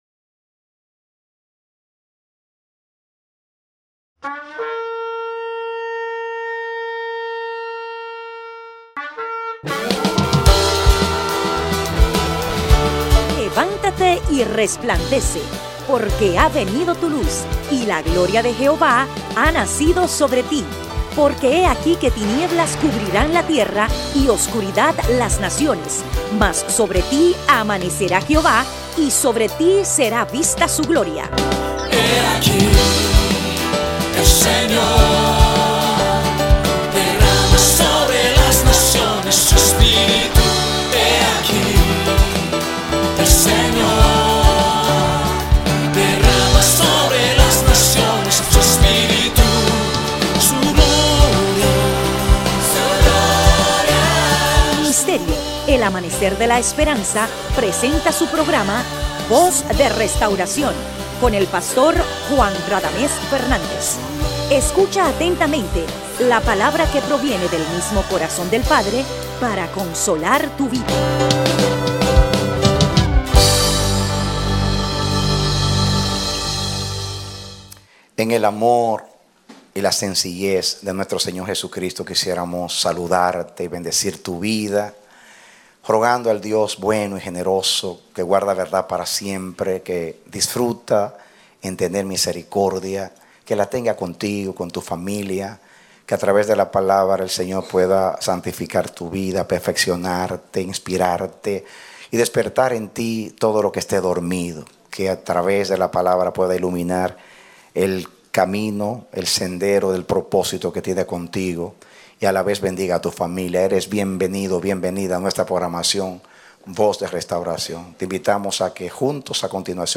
Predicado Jueves 28 de Enero, 2016